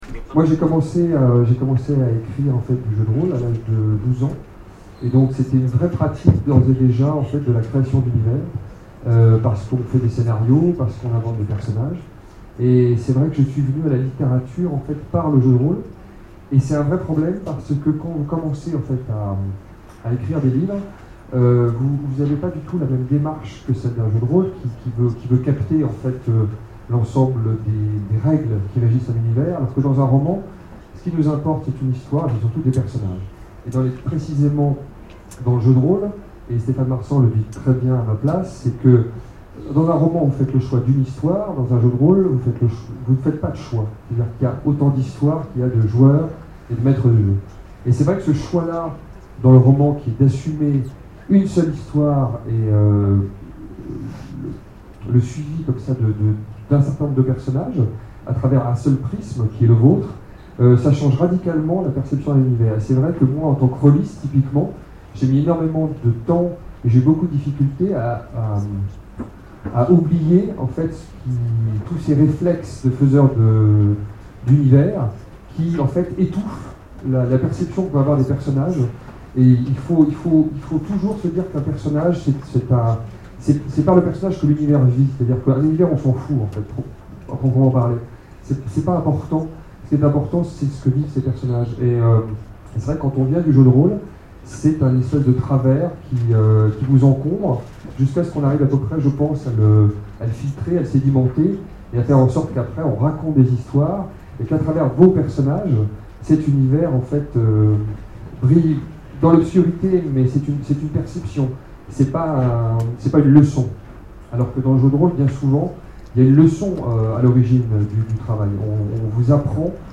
Imaginales 2013 : Conférence Créateurs d'univers...